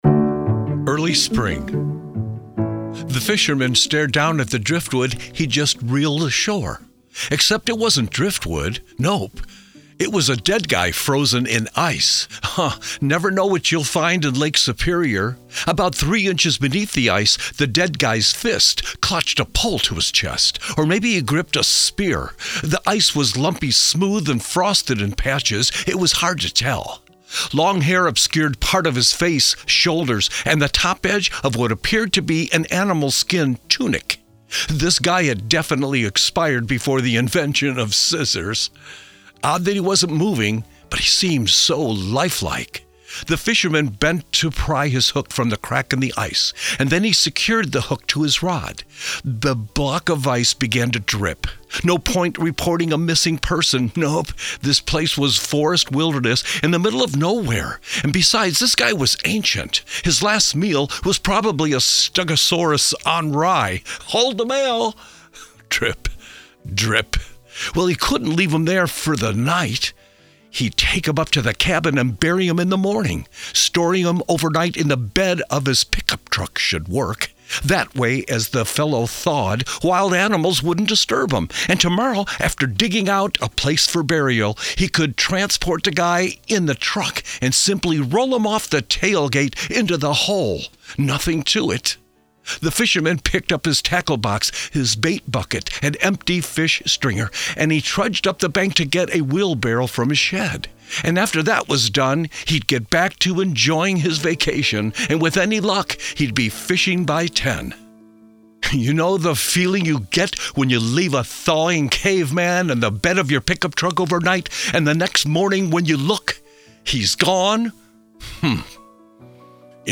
Hear an excerpt read